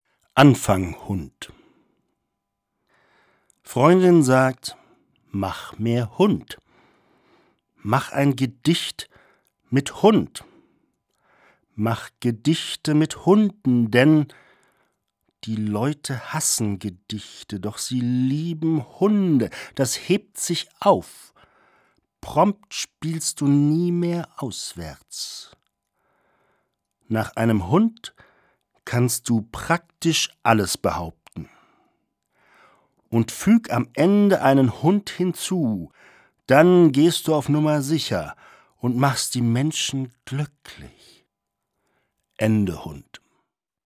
Das radio3-Gedicht der Woche: Dichter von heute lesen radiophone Lyrik.
Gelesen von Helmut Krausser.